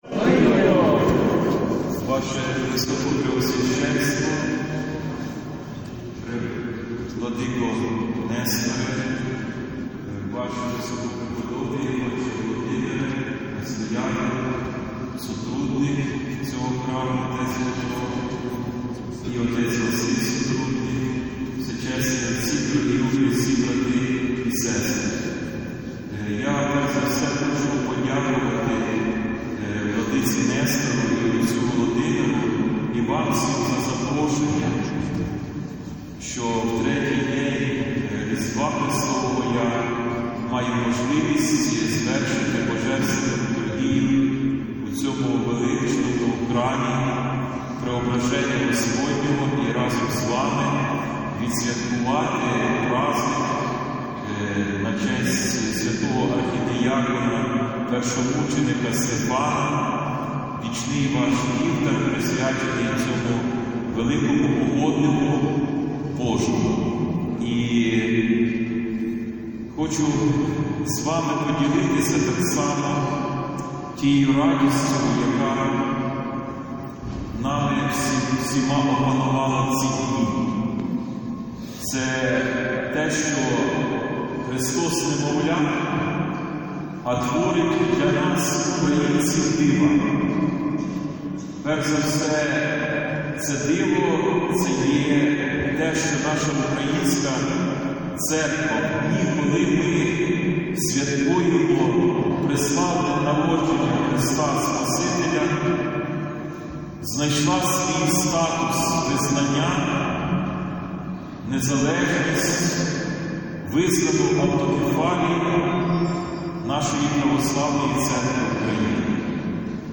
Богослужіння у день пам’яті первомученика Стефана у Кременці
Цього святкового дня храм був переповнений людьми.
Після молебню до первомученика архідиякона Стефана з повчальним словом до присутніх звернувся митрополит Димитрій.